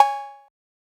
MANNIE_FRESH_cow_bell_one_shot_long.wav